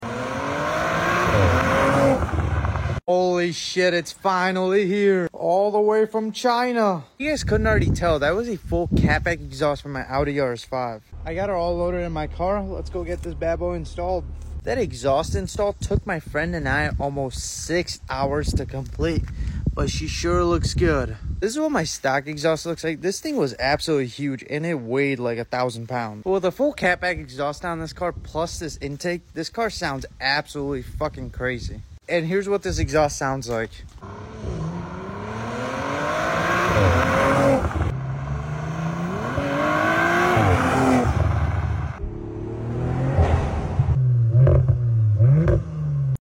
I Finally Put A Exhaust Sound Effects Free Download